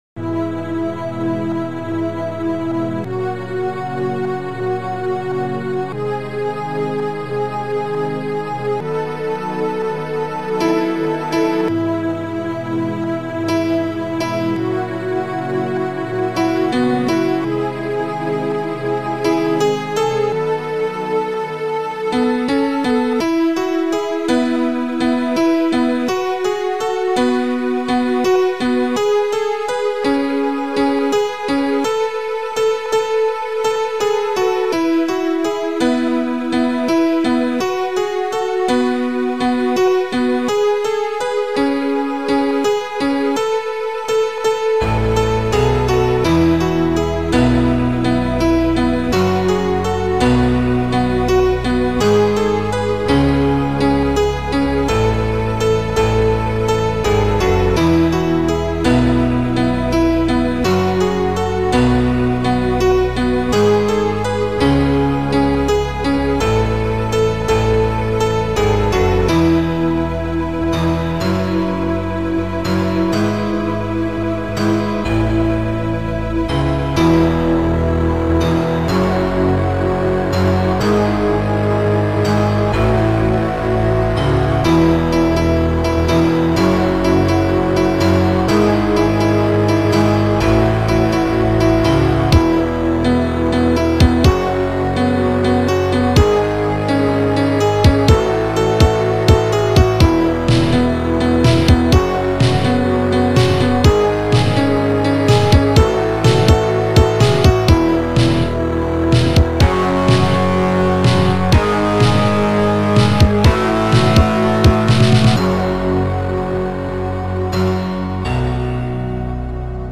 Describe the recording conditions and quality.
auf einem Amiga 500 & Amiga 4000.